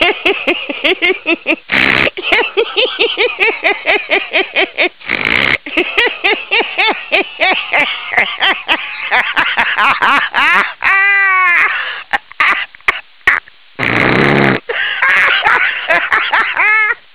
LAUGHING - This is a strange laugh which most people would find strange.
laugh.wav